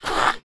Index of /App/sound/monster/orc_magician
damage_1.wav